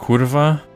Polish Swear. 33 Ansichten.